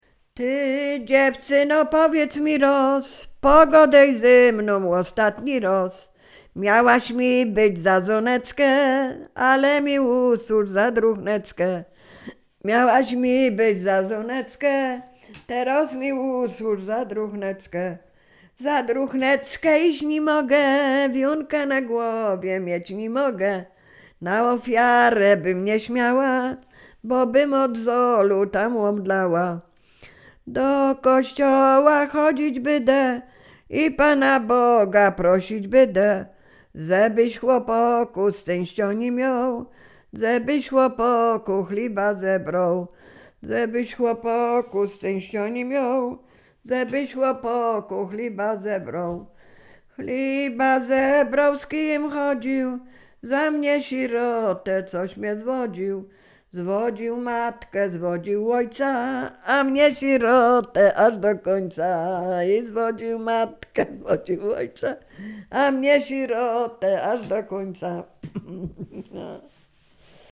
Sieradzkie
liryczne miłosne